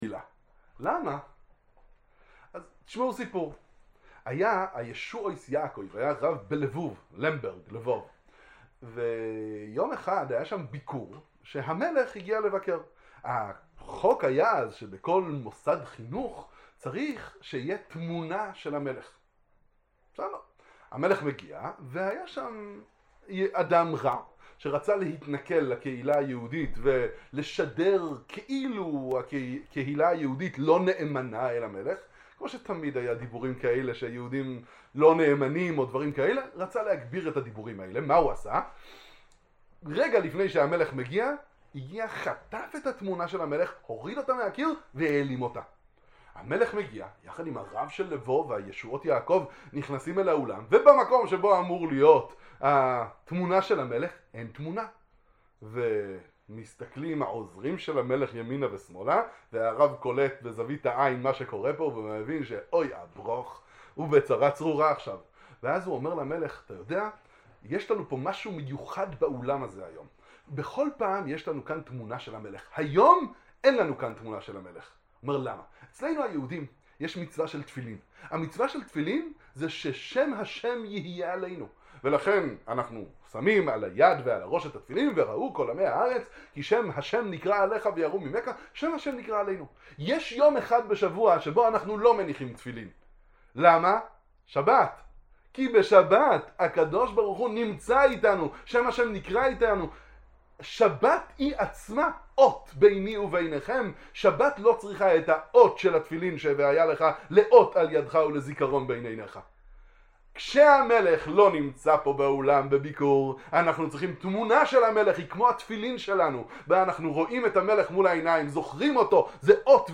דבר תורה קצר ומחזק